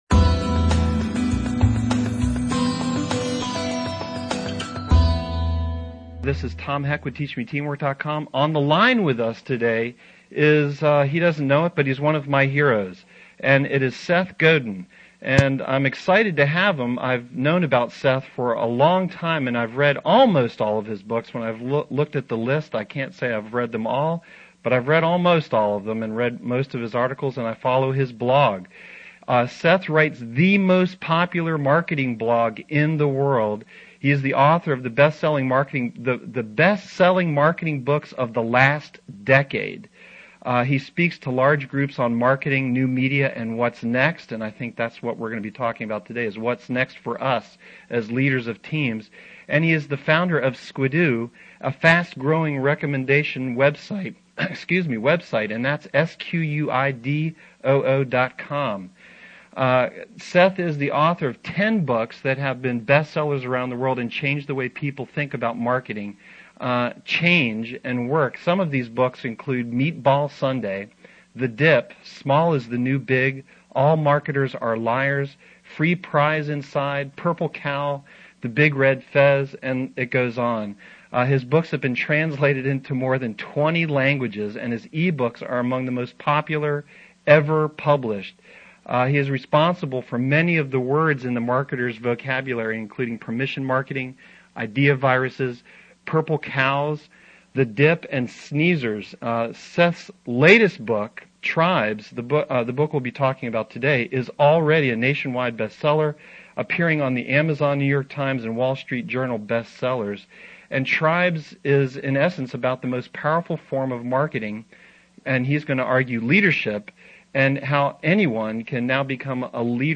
seth godin tribes audio interview
Learn from Seth Godin in this live and interactive TeleSeminar.